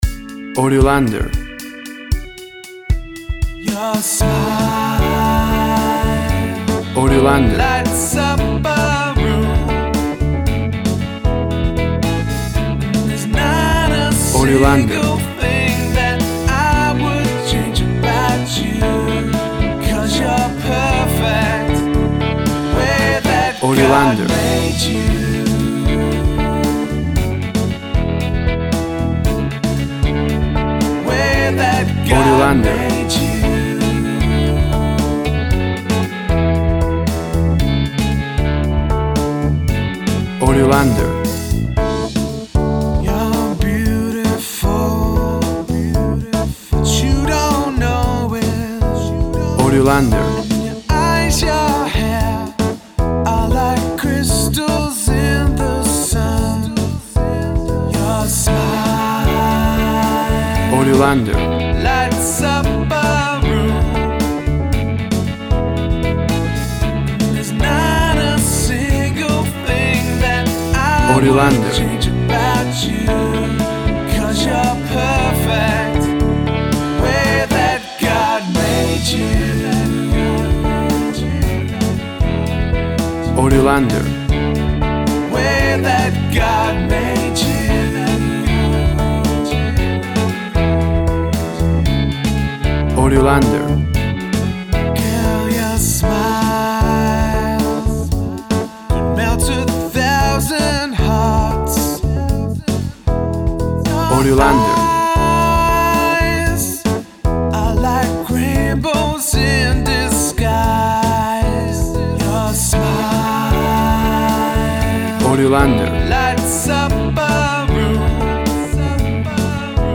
Tempo (BPM) 115